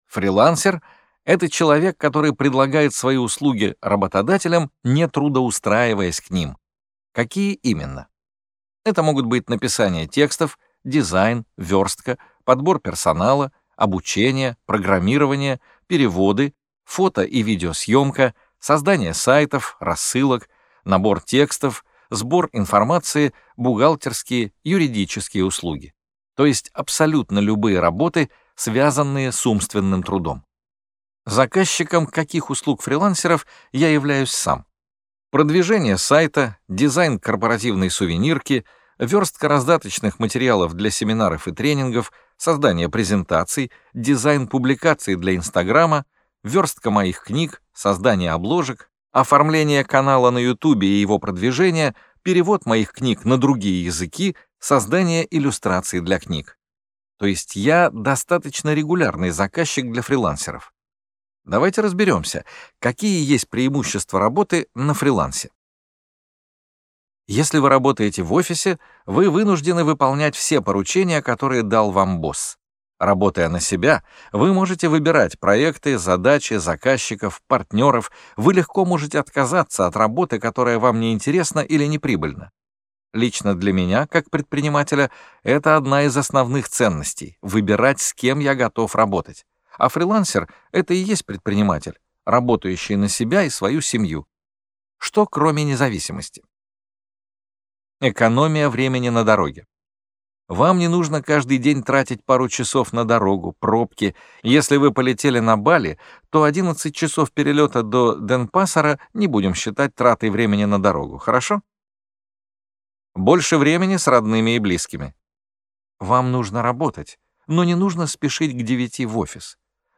Аудиокнига Фрилансер. Путь к свободе, за которую платят | Библиотека аудиокниг